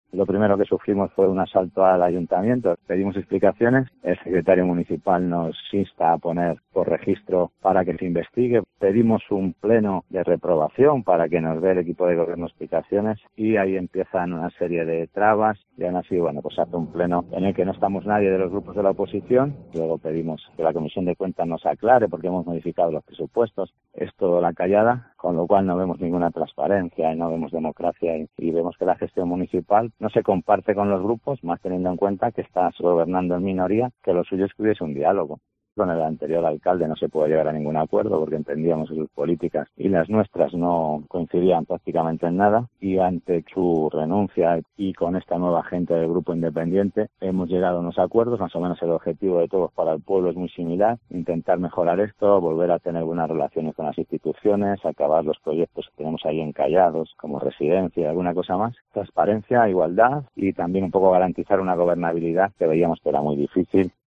Entrevista al candidato del PSOE a la Alcaldía de Almoguera tras la moción de censura